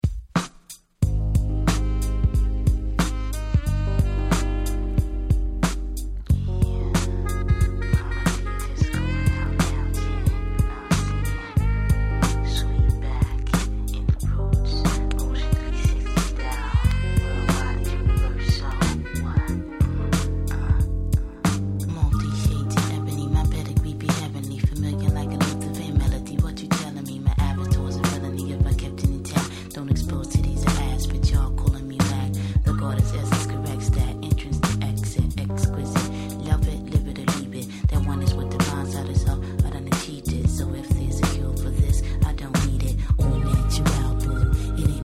幻想的でフワフワと気持ちの良いトラックが凄くクセになります！